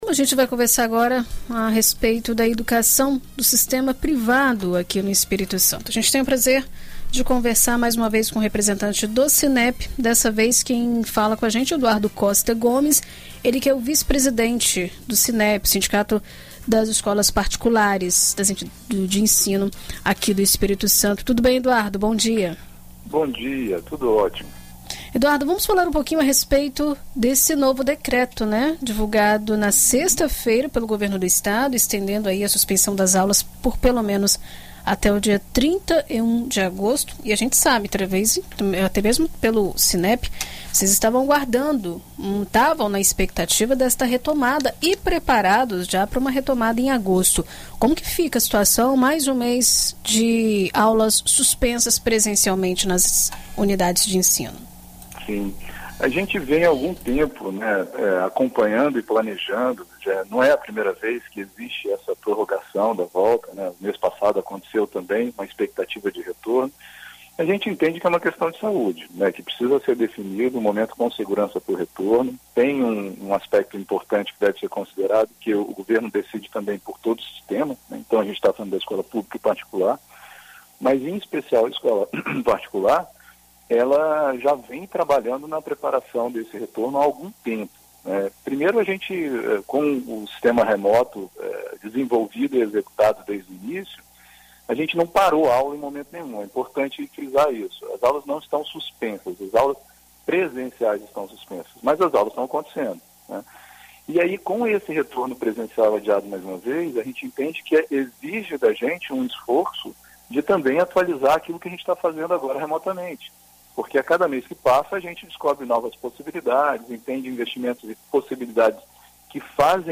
Em entrevista à BandNews FM Espírito Santo